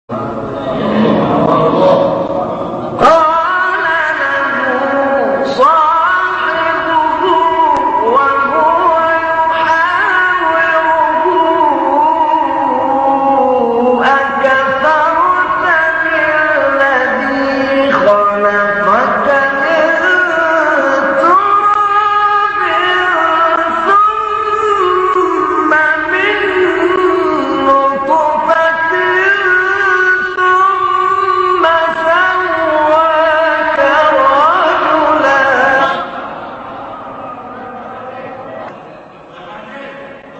گروه شبکه اجتماعی: مقاطع صوتی از تلاوت‌های قاریان برجسته مصری را می‌شنوید.